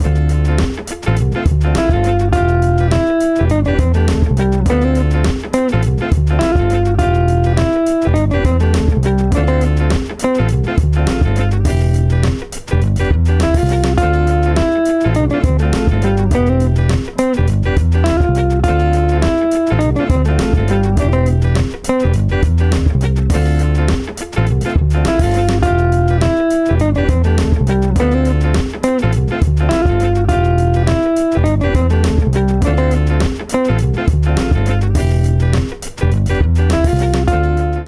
(0:37) Some garage funky jazzy stuff